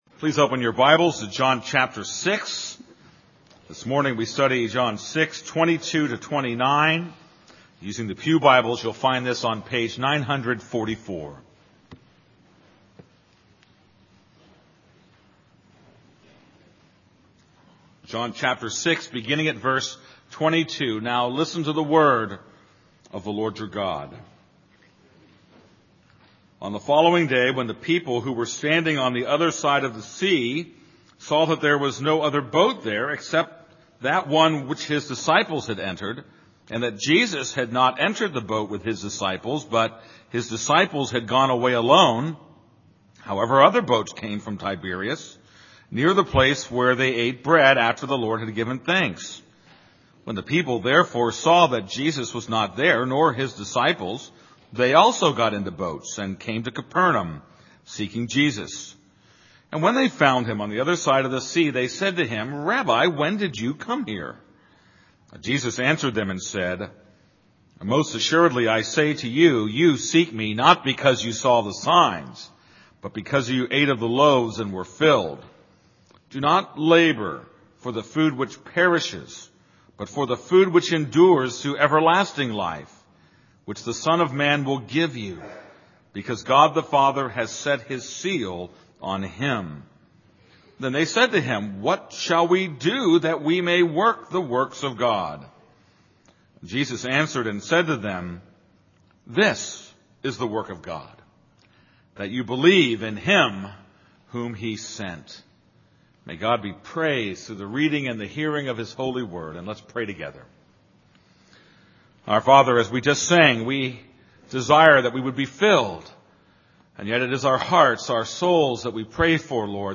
This is a sermon on John 6:22-29.